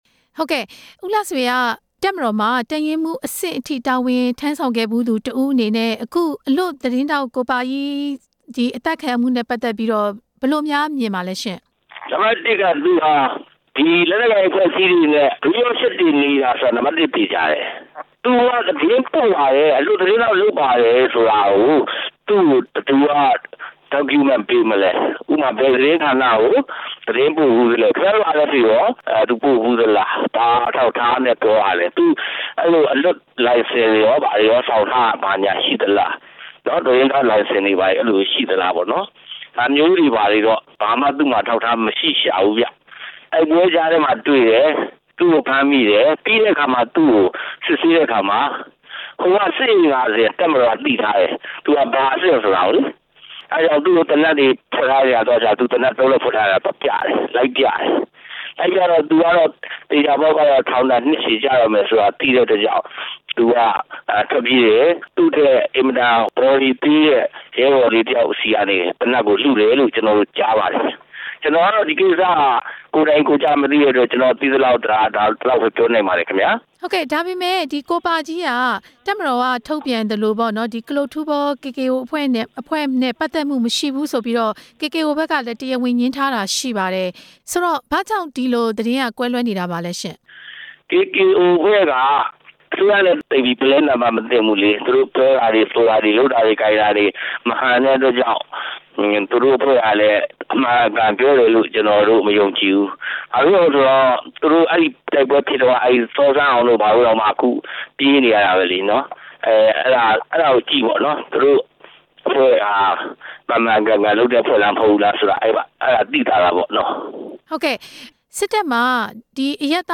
ဆက်သွယ်မေးမြန်းထားတာ